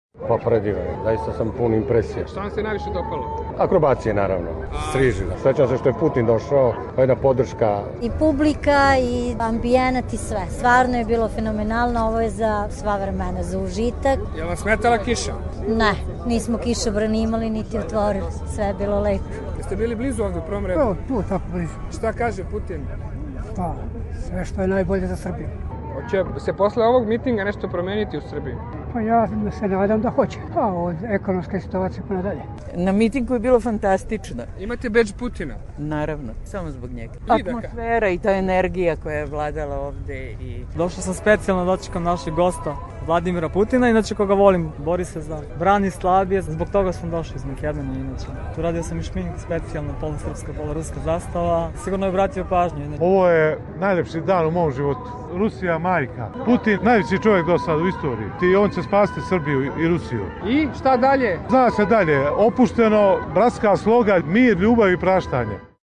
Ovacije Vladimiru Putinu, Rusiji i Srbiji, prijateljske poruke dvojice predsednika i vazduhoplovni spektakl obeležili su vojnu paradu u Beogradu. Pojavljivanje predsednika Rusije, uz zvuke fanfara, pratilo je skandiranje publike